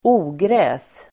Uttal: [²'o:grä:s]